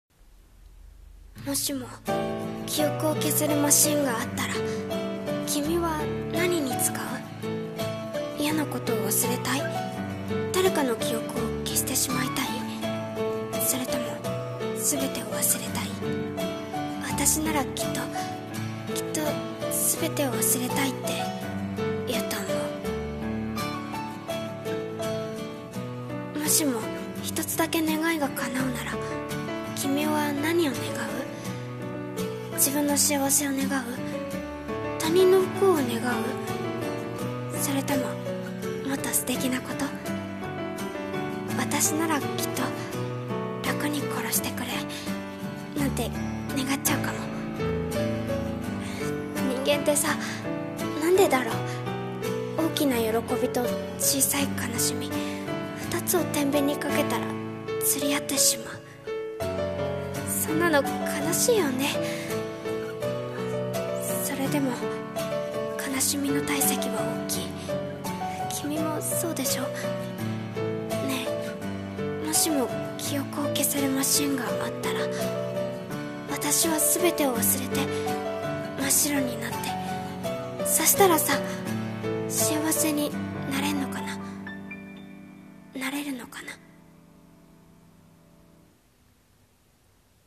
ねぇ、もしも 声劇